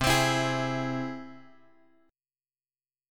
C Augmented
C+ chord {x 3 x 5 5 4} chord
C-Augmented-C-x,3,x,5,5,4.m4a